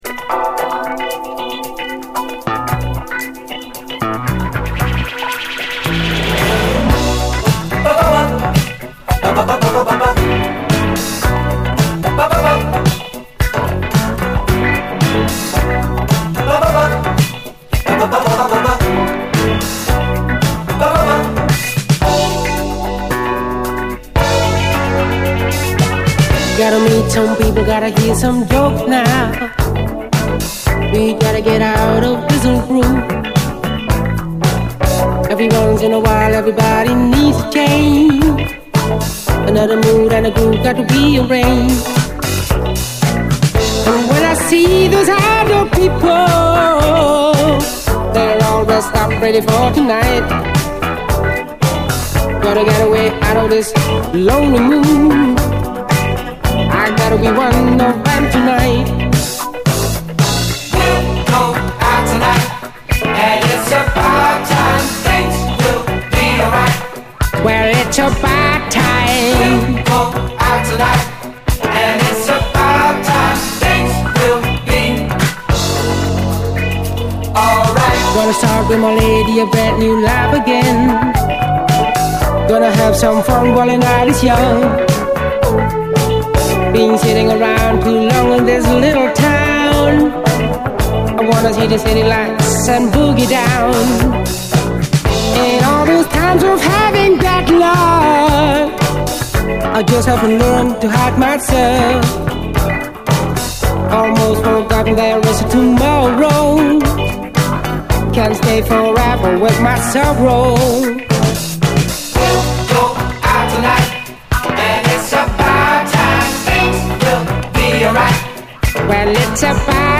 SOUL, 70's～ SOUL, 7INCH
オランダ産アーバン・ブギー・モダン・ソウル！